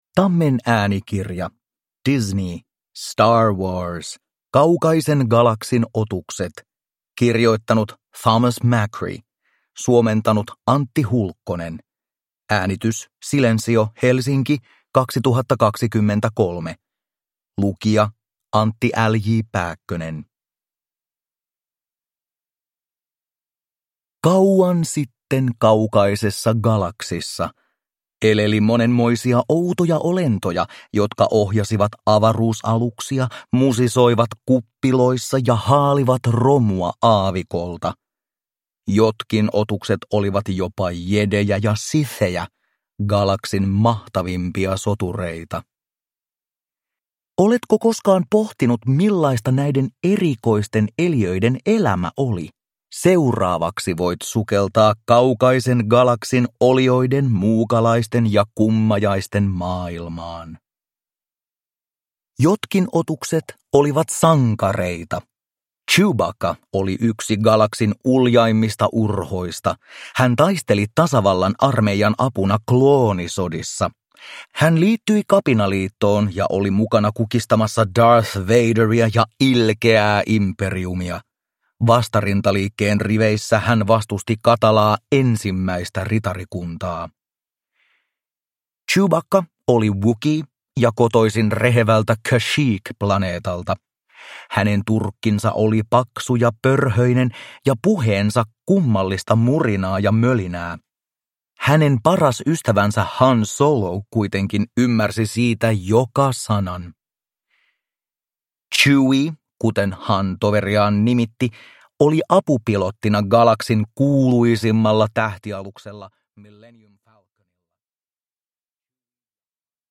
Star Wars. Kaukaisen galaksin otukset – Ljudbok